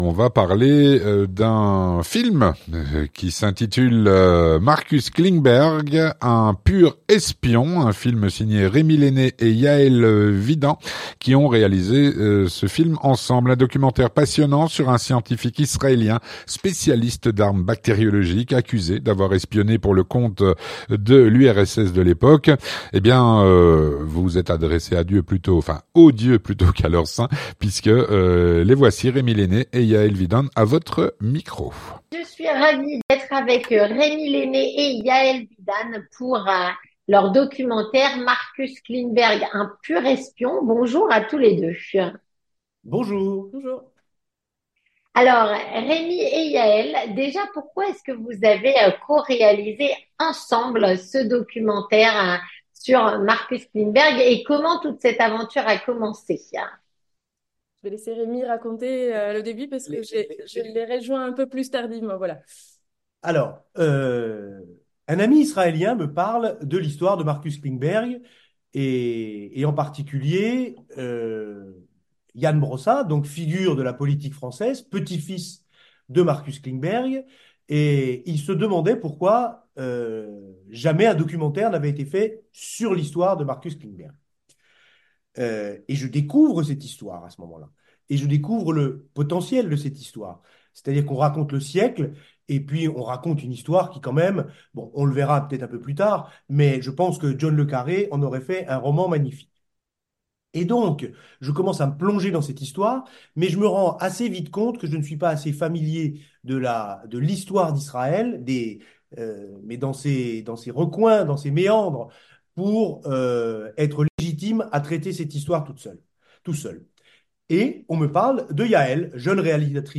les 2 co-réalisateurs de ce film.